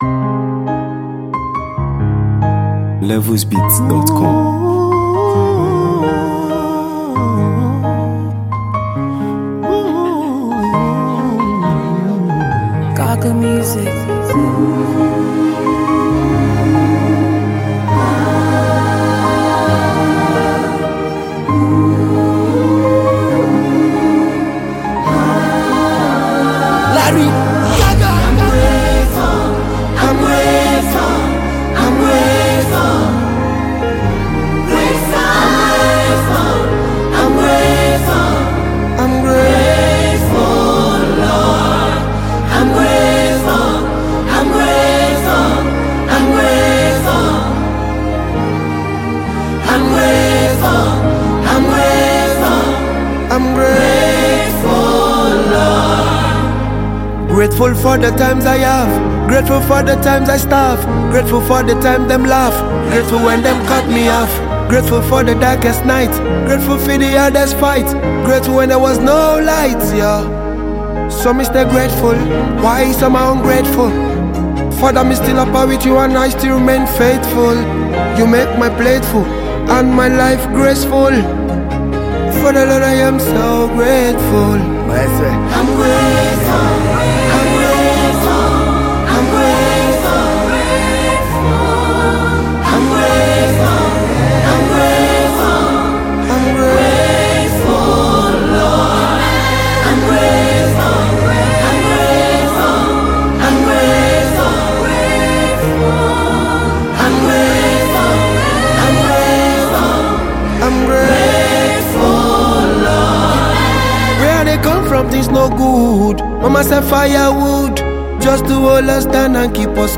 Nigeria Music
delivers a powerful and uplifting record